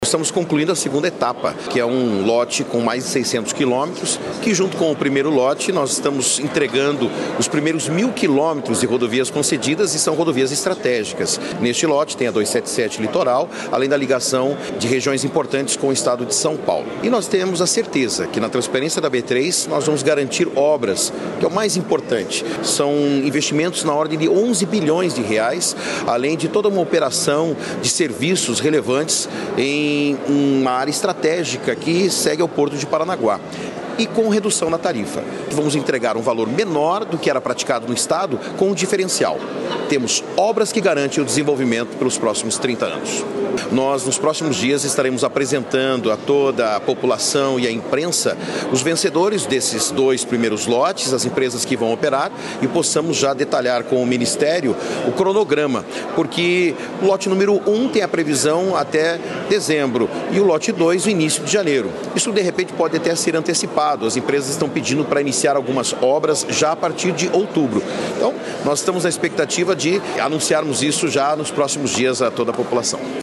Sonora do secretário de Infraestrutura e Logística, Sandro Alex, sobre a realização do leilão do Lote 2 das novas concessões rodoviárias do Paraná